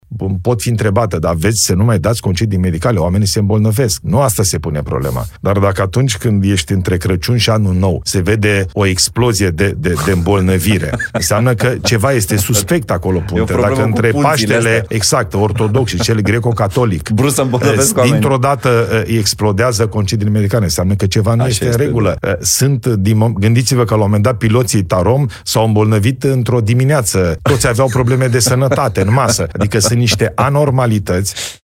Într-un interviu pentru podcastul ApropoTv, Ilie Bolojan spune că țara a plătit anul acesta șase miliarde de lei pentru concediile medicale luate de români. El a subliniat că problema nu este boala reală, ci tiparele statistice suspecte.